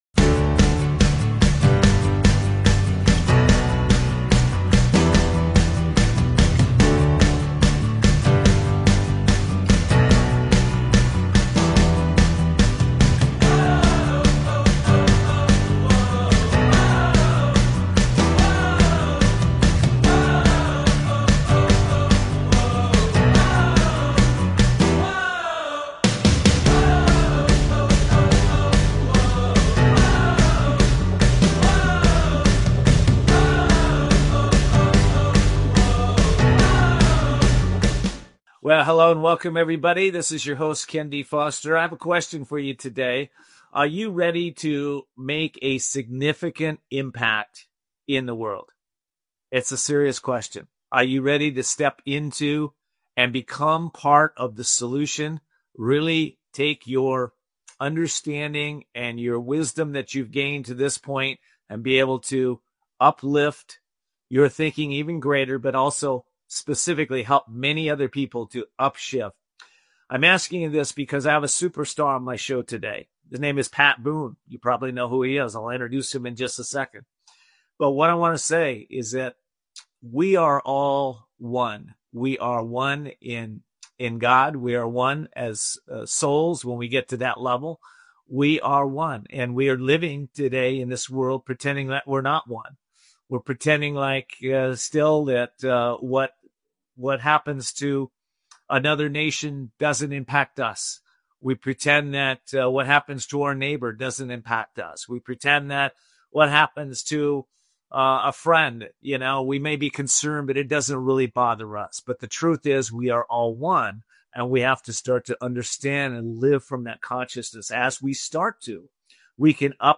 Talk Show Episode, Audio Podcast, Voices Of Courage and S4EP10, Inspire A Legacy Of Faith Music And Resilience Pat Boone on , show guests , about Inspire A Legacy Of Faith Music And Resilience,Pat Boone, categorized as Performing Arts,History,Music,Philosophy,Society and Culture